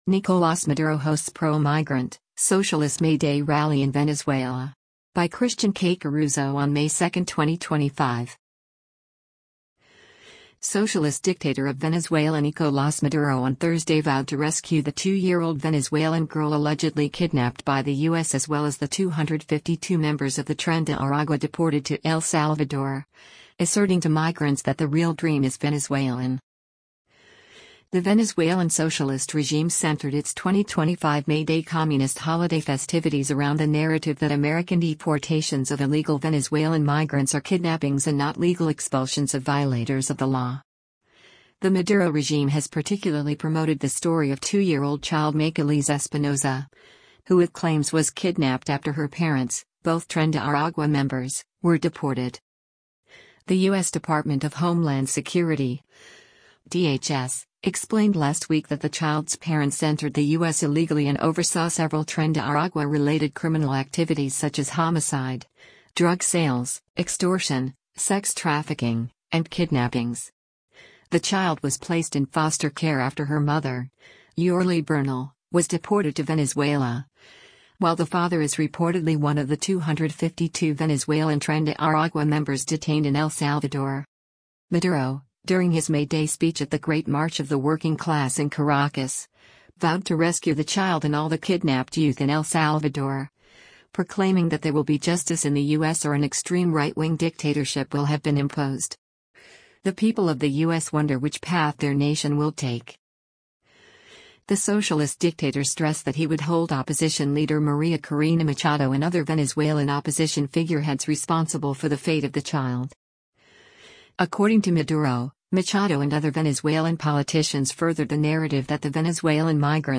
Nicolás Maduro Hosts Pro-Migrant, Socialist May Day Rally in Venezuela
Maduro, during his May Day speech at the “Great March of the Working Class” in Caracas, vowed to rescue the child and all the “kidnapped youth” in El Salvador, proclaiming that “there will be justice in the U.S. or an extreme right-wing dictatorship will have been imposed. The people of the U.S. wonder which path their nation will take.”